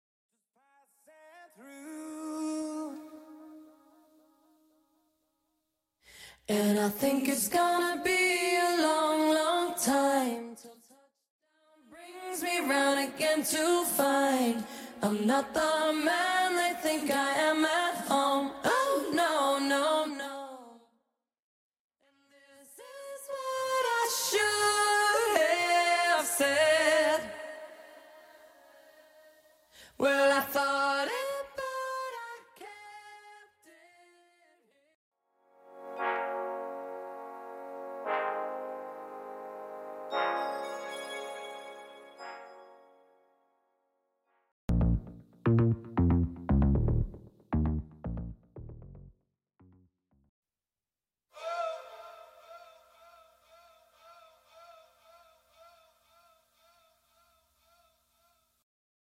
Backing Vocals, Adlibs & Horn Pads Stem
Bassline Stem
Guitars, Bells & Strings Stem
Percussion & Drums Stem